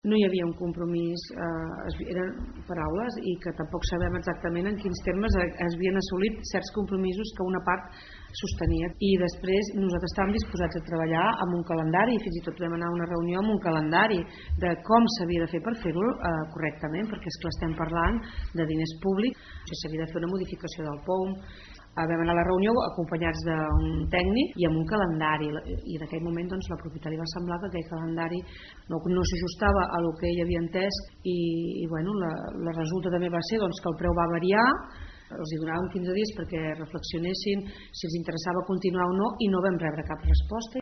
Neus Serra és la portaveu del govern de Malgrat de Mar.